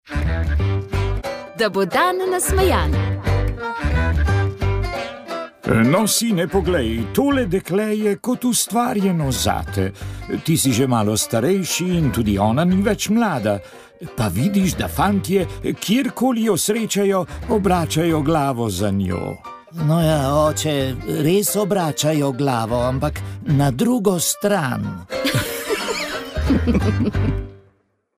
Osem let nazaj je v Murski Soboti pod okriljem tamkajšnje Škofijske karitas začela delovati prva Ljudska kuhinja v Pomurju. O projektu in prejemnikih toplih obrokov smo se pogovarjali